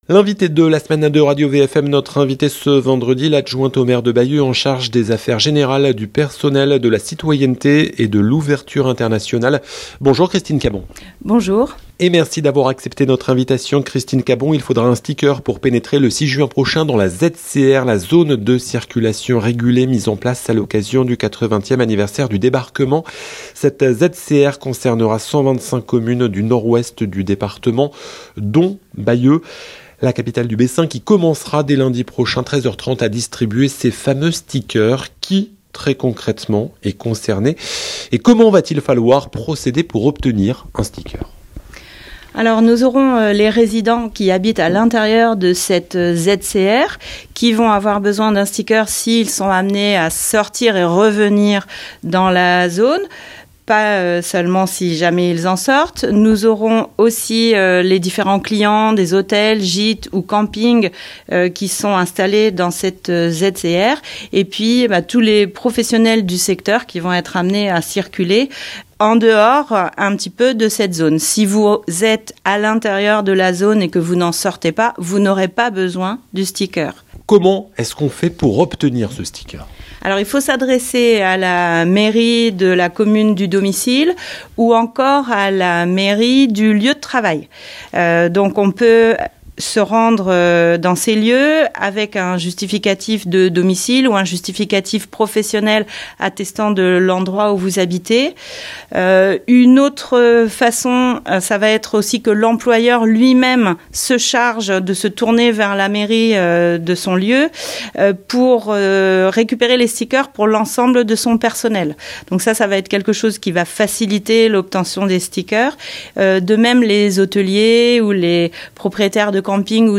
Christine Cabon Christine Cabon , l'adjointe au maire de Bayeux, en charge des affaires générales, du personnel, de la citoyenneté et de l'ouverture internationale est l'invitée de la rédaction de Radio VFM ce vendredi 19 avril 2024.